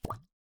hud_bubble.ogg